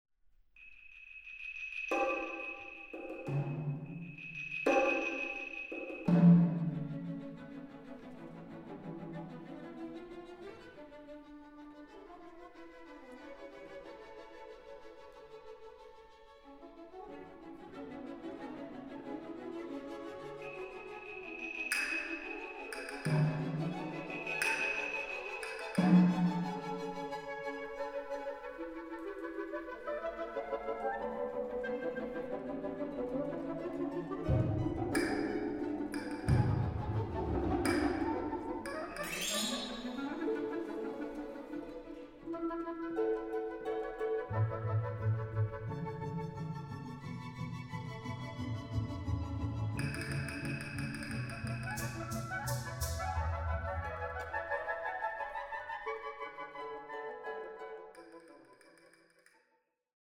Recording: Katharina-Saal, Stadthalle Zerbst, 2025
für großes Orchester